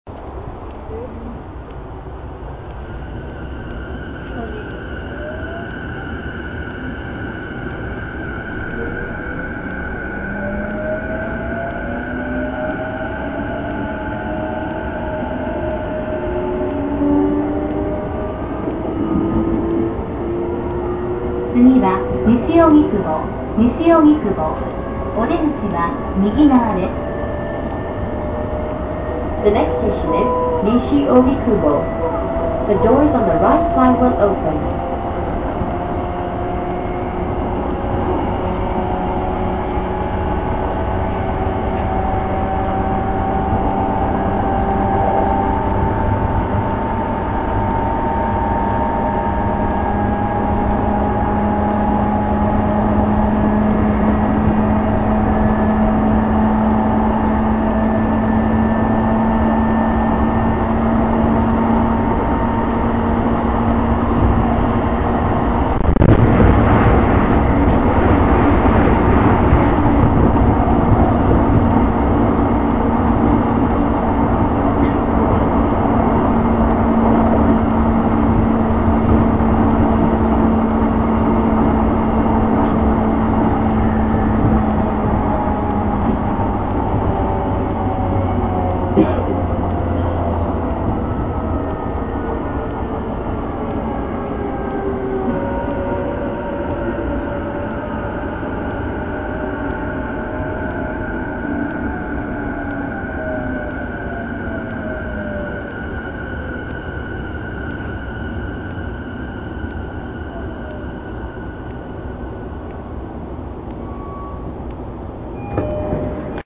モハ車走行音|荻窪〜西荻窪|
ogikubo-nishiogikubo.mp3